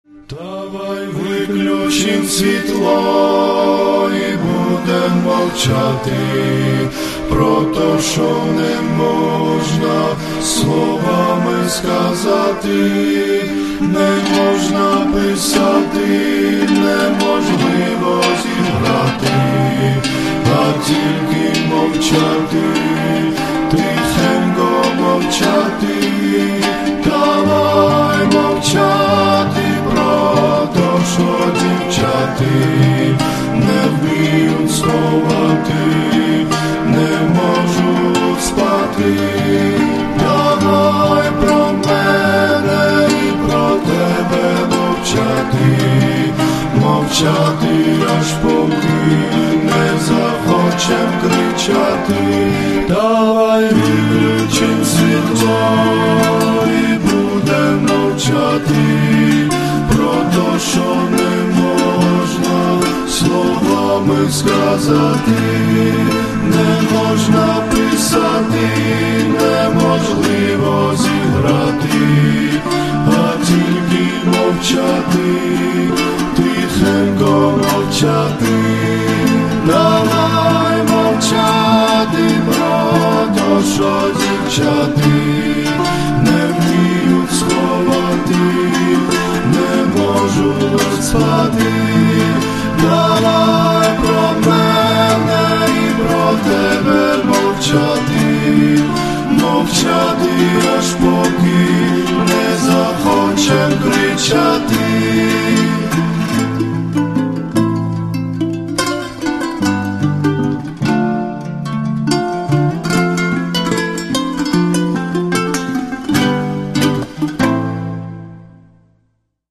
• Качество: 112, Stereo
спокойные
красивая мелодия
романтичные
кавер